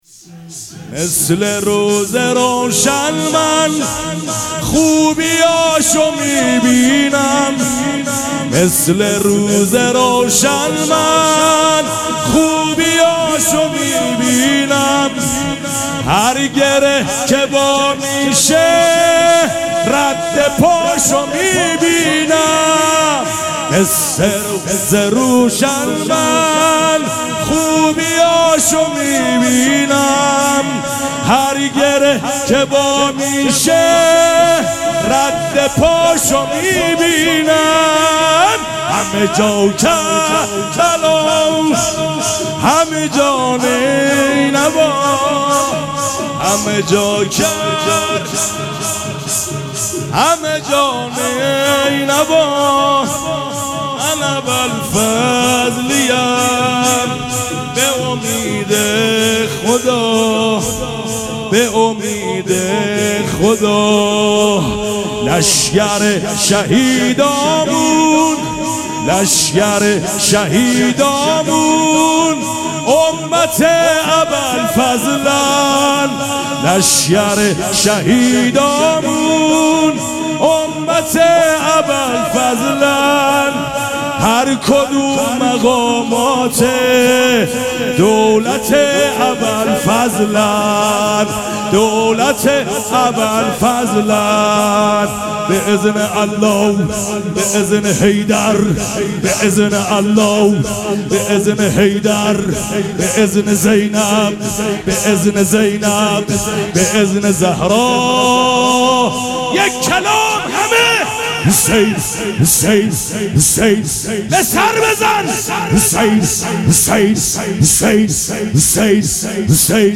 شب اول مراسم عزاداری اربعین حسینی ۱۴۴۷
شور
مداح